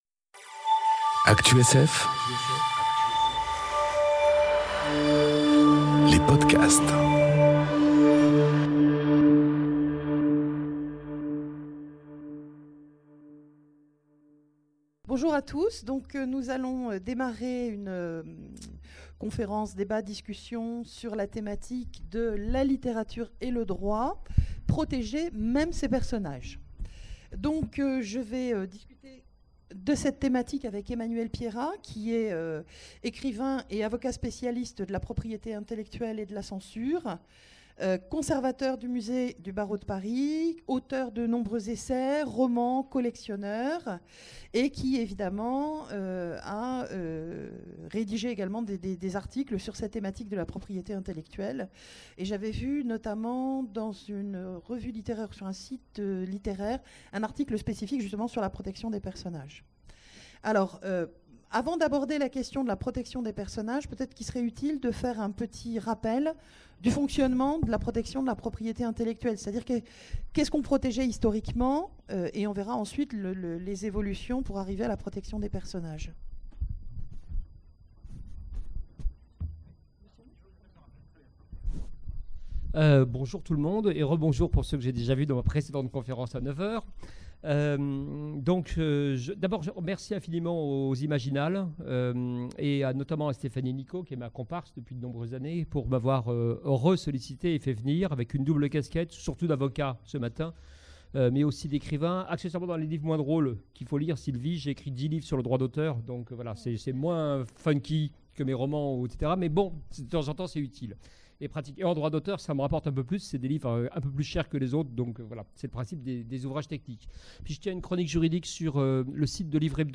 Conférence La littérature et le droit : protéger même ses personnages ? enregistrée aux Imaginales 2018